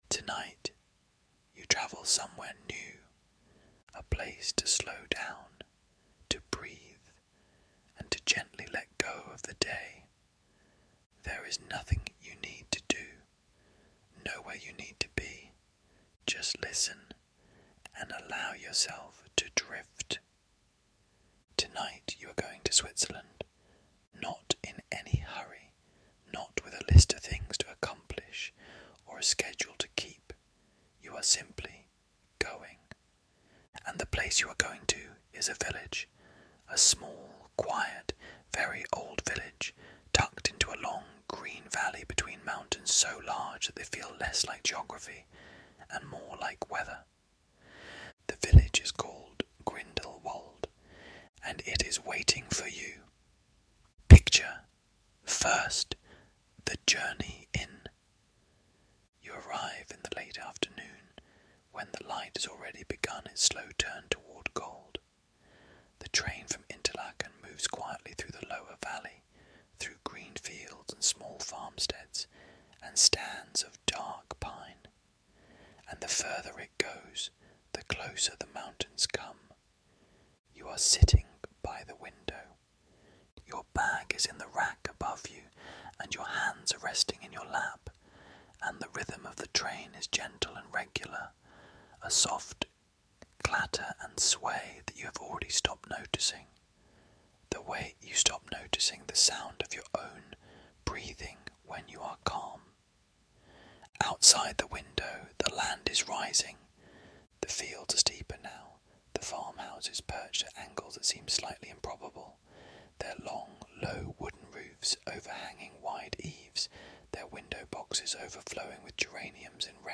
Free sleep stories for adults, available through your favourite podcast platforms. Prompted by humans, written and read by AI.